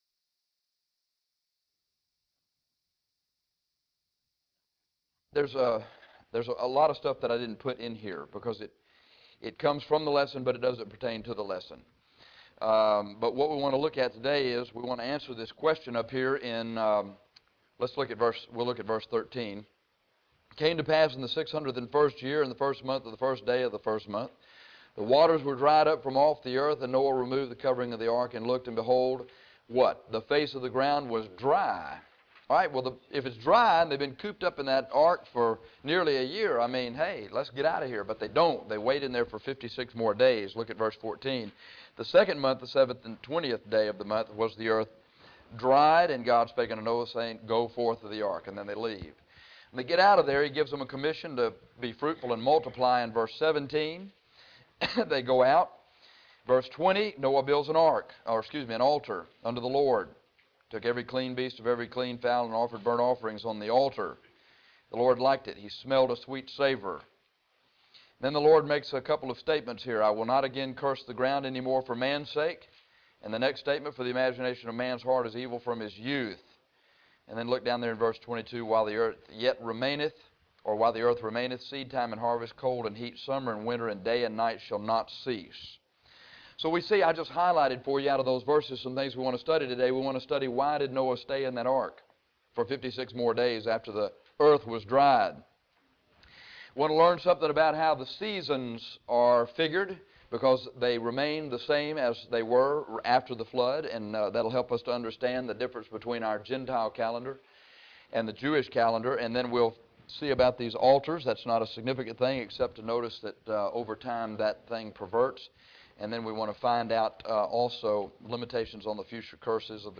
In this lesson, we will see why Noah stayed in the ark 56 days after the earth was dry. We will also see the four seasons, the first altar built to God, the limitation on future curses and the true condition of man’s heart.